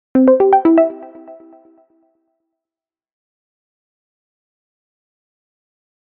Scifi 5.mp3